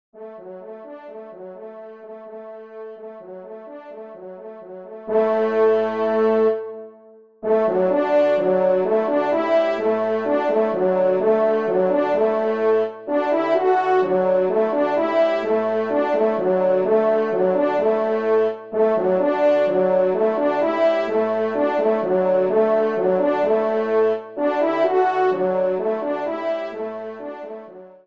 TON SIMPLE :
Pupitre 2° Cor (en exergue)